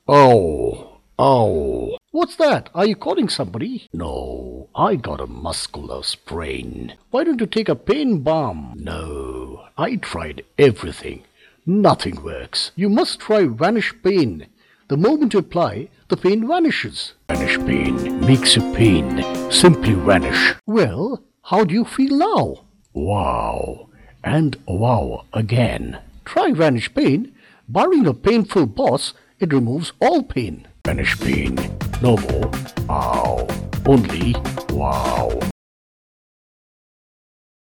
indisches engl.
Sprechprobe: Sonstiges (Muttersprache):